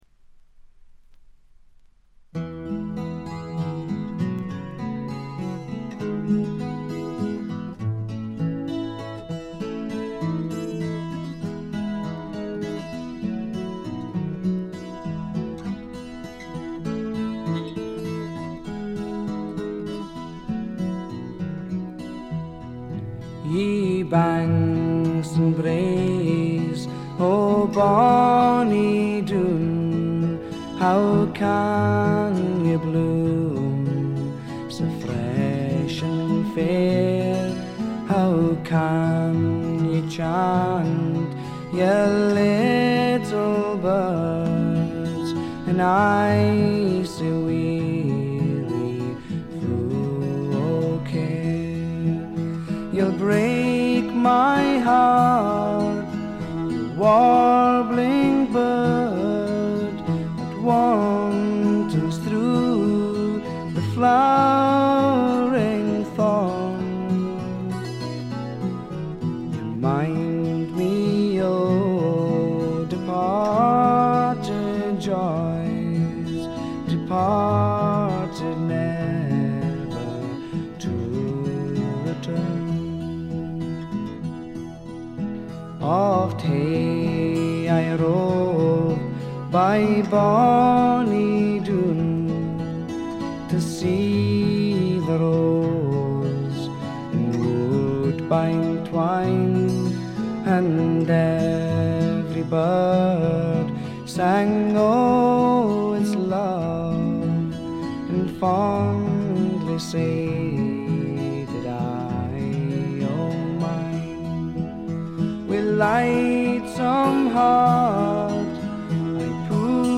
ほとんどノイズ感無し。
試聴曲は最大の魅力である哀愁味あふれる沁みる歌声に焦点を当てましたが、この人は楽器の腕前も一級品です。
試聴曲は現品からの取り込み音源です。